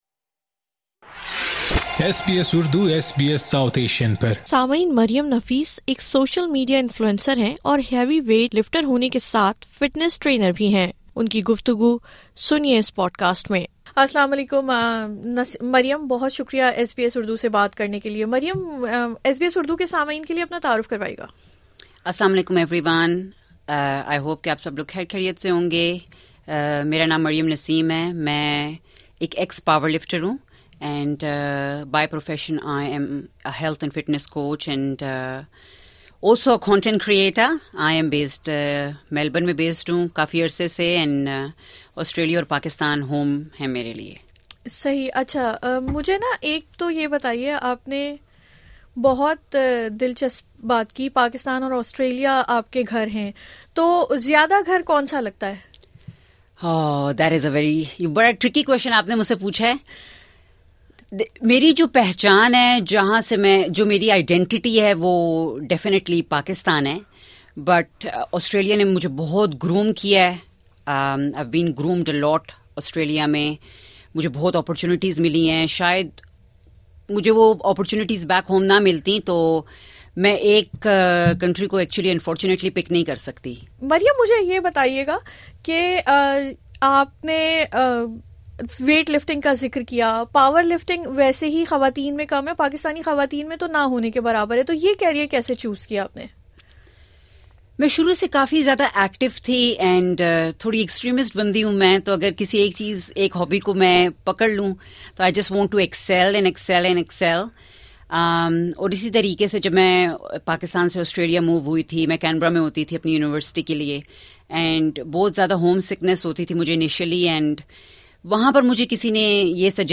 In her conversation with SB Urdu,she articulated the importance of exercise as an integral part of life, much like eating and drinking. She highlighted that exercise should not be neglected or eliminated; rather, it should remain a primary focus as one ages.